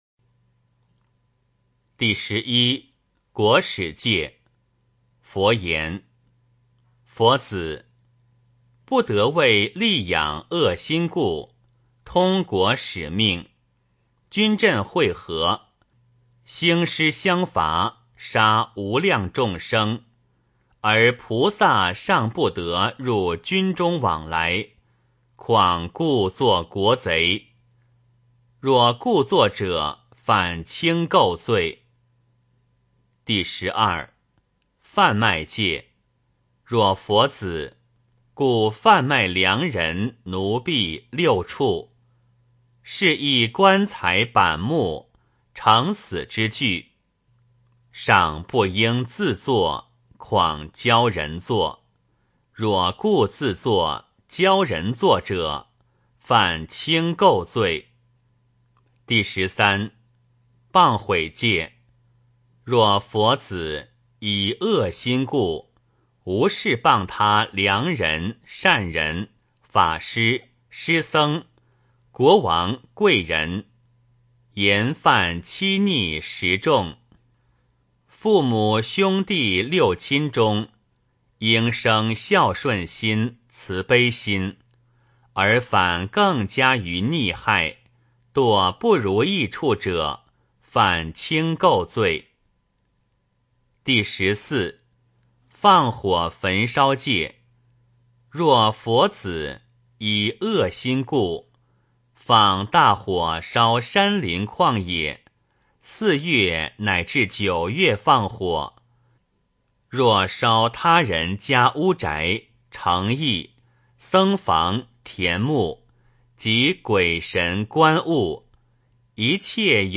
梵网经-四十八轻戒 诵经 梵网经-四十八轻戒--未知 点我： 标签: 佛音 诵经 佛教音乐 返回列表 上一篇： 梵网经-四十八轻戒 下一篇： 永嘉证道歌 相关文章 《妙法莲华经》陀罗尼品第二十六 《妙法莲华经》陀罗尼品第二十六--佚名...